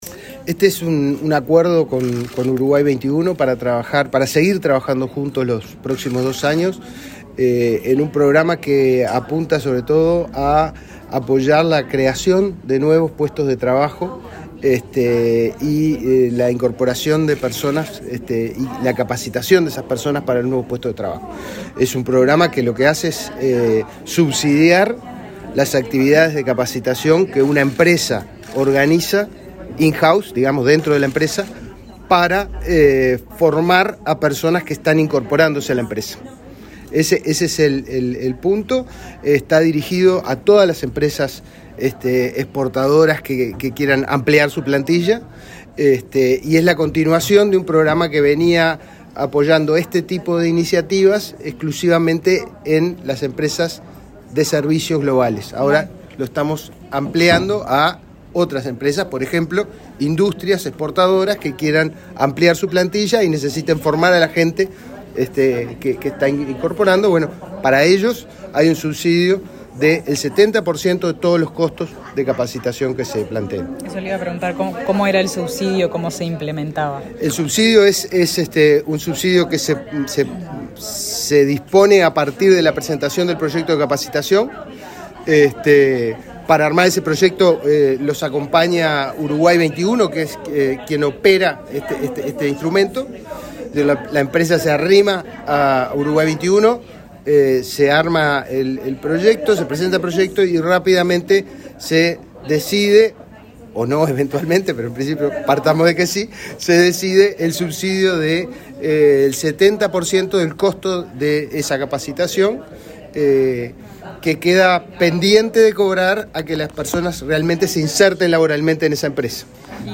Declaraciones del director del Inefop, Pablo Darscht
Declaraciones del director del Inefop, Pablo Darscht 15/12/2022 Compartir Facebook X Copiar enlace WhatsApp LinkedIn El director ejecutivo de Uruguay XXI, Sebastián Risso; el director general del Instituto Nacional de Empleo y Formación Profesional (Inefop), Pablo Darscht, y el ministro de Trabajo, Pablo Mieres, participaron en la firma de un acuerdo de cooperación interinstitucional. Luego Darscht dialogó con la prensa.